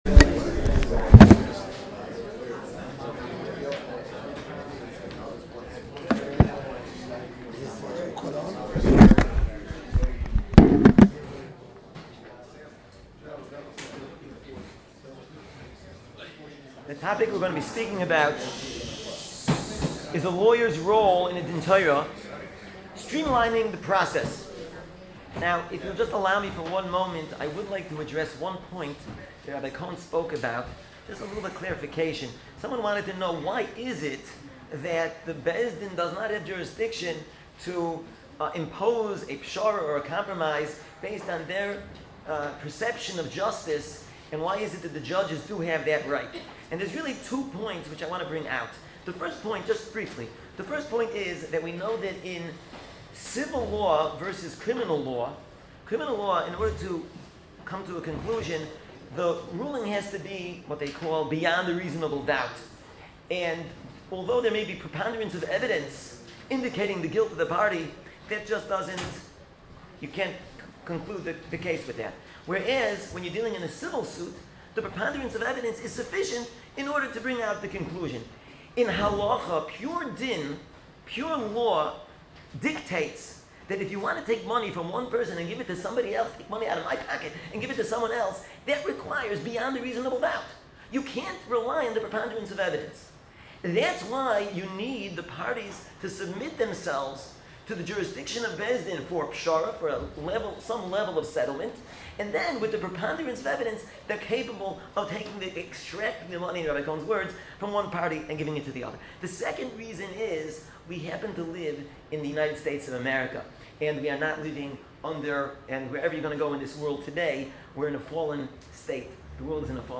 CLE symposium June 14, 2015